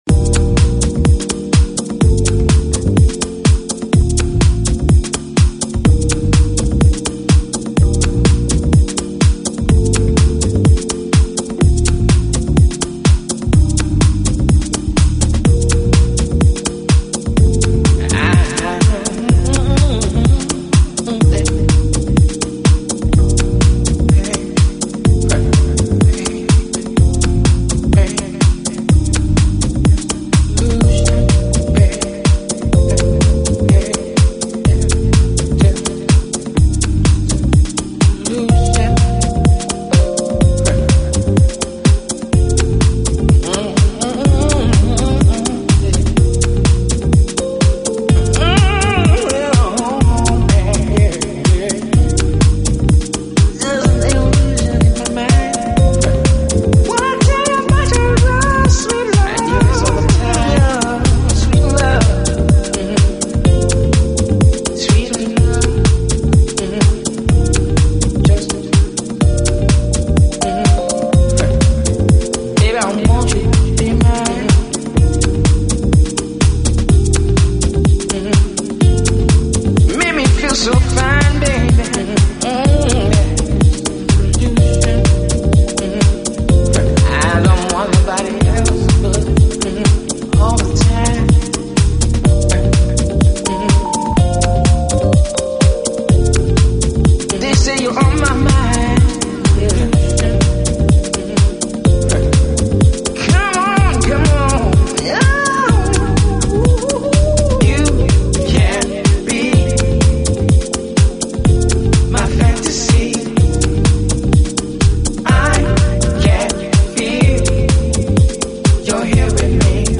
来自时尚之都的慵懒调调
音乐类型: Chill-Out, Lo-Fi, House
A side的风格是lounge，B side则是house。